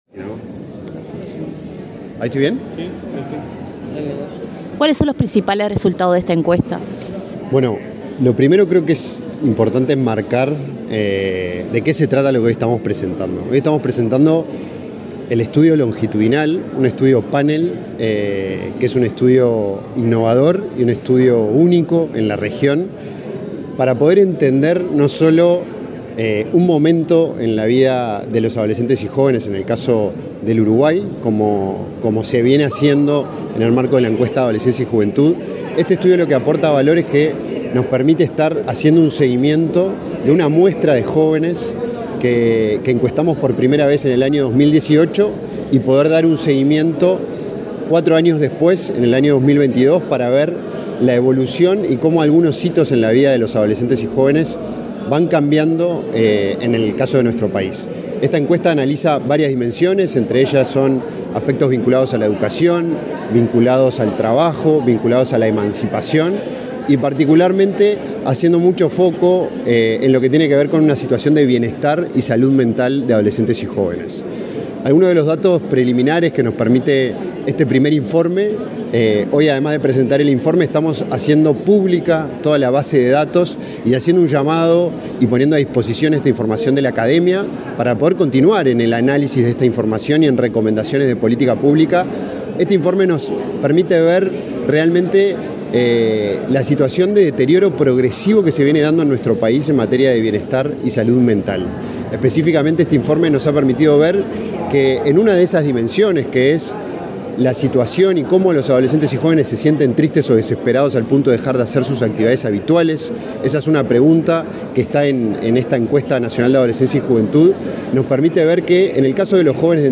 Declaraciones del director del INJU, Felipe Paullier
Declaraciones del director del INJU, Felipe Paullier 12/10/2023 Compartir Facebook X Copiar enlace WhatsApp LinkedIn El director del Instituto Nacional de la Juventud (INJU), Felipe Paullier, dialogó con la prensa antes de participar en la presentación del primer informe del Panel de Juventudes, una publicación inédita en Uruguay y la región.